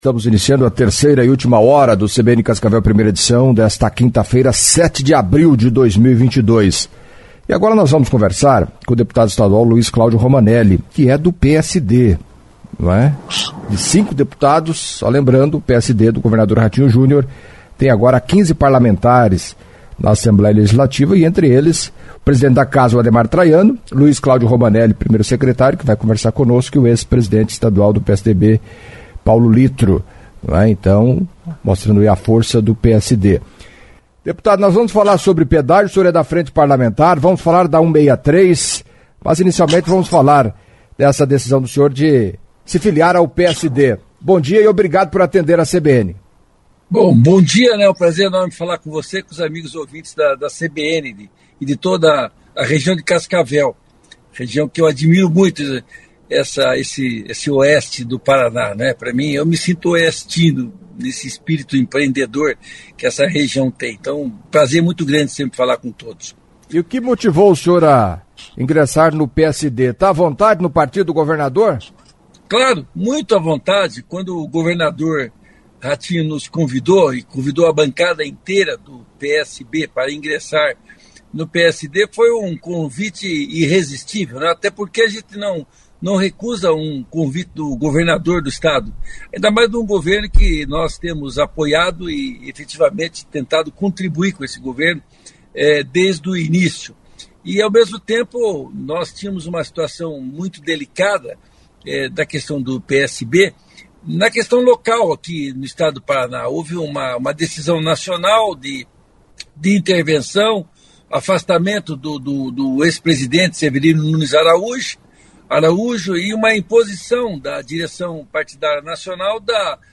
Em entrevista à CBN Cascavel nesta quinta-feira (07) o deputado estadual Luiz Claudio Romanelli falou, entre outros assuntos, da paralisação das obras de duplicação da 163 e retomada do debate em torno do pedágio na audiência marcada para o próximo dia 13 de abril na Assembleia Legislativa. Sobre o ingresso no PSD disse estar confortável.